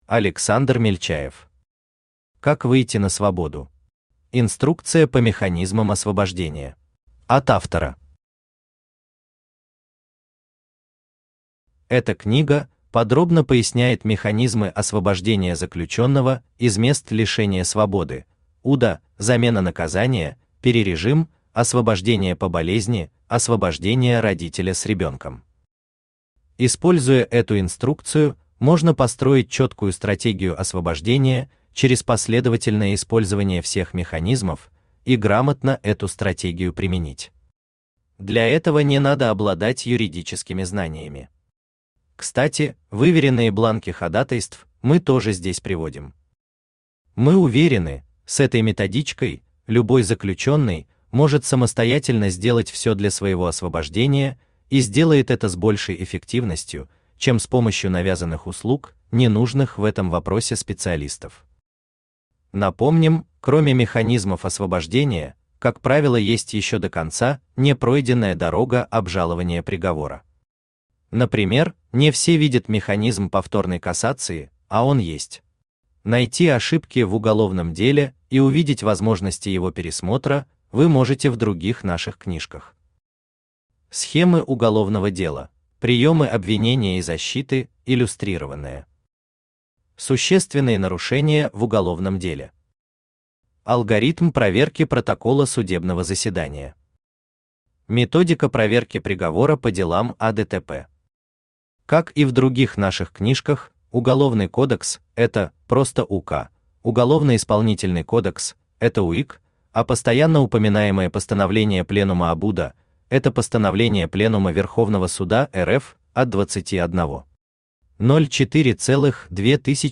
Инструкция по механизмам освобождения Автор Александр Алексеевич Мельчаев Читает аудиокнигу Авточтец ЛитРес.